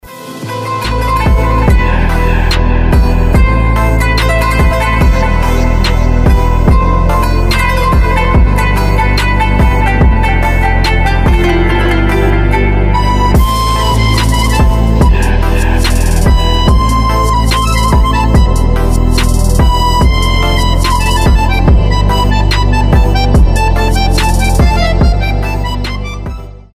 без слов
аккордеон
восточные
биты
азербайджанские
Мелодичная битовая музыка на звонок